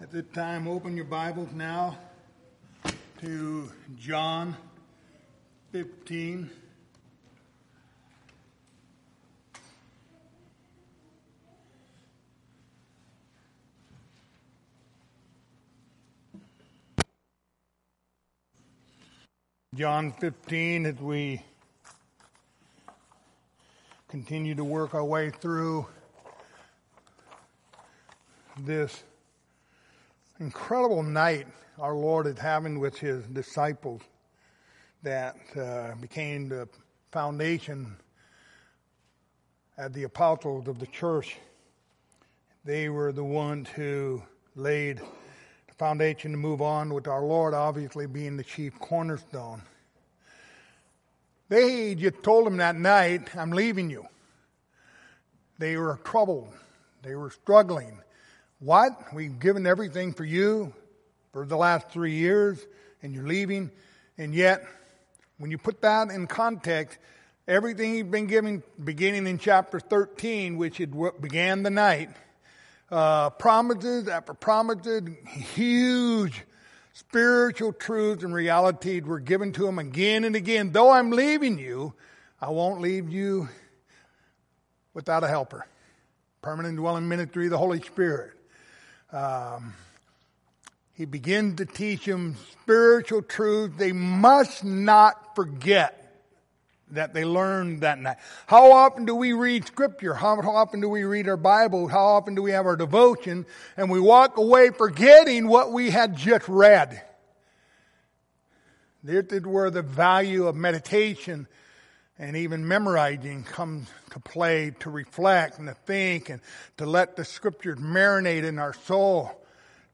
Passage: John 15:15-17 Service Type: Wednesday Evening Topics